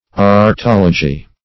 Search Result for " aretology" : The Collaborative International Dictionary of English v.0.48: Aretology \Ar`e*tol"o*gy\, n. [Gr.